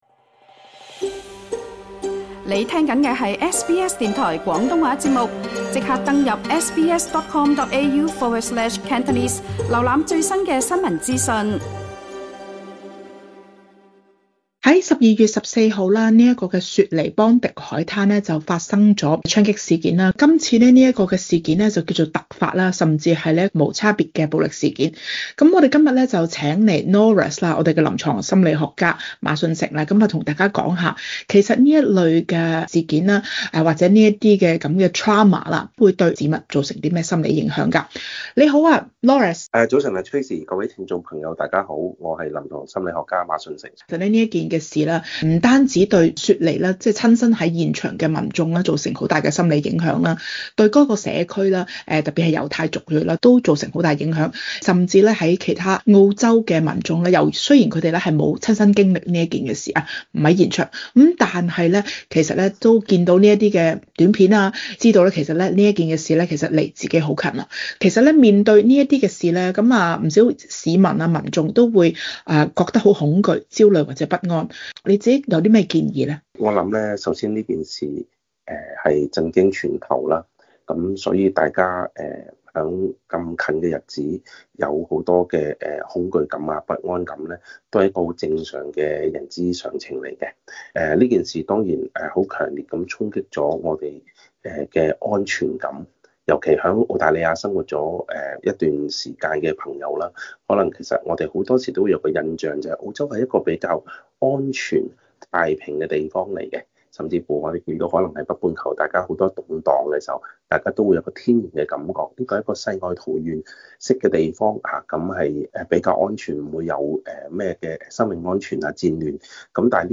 詳情請收聽這節訪問。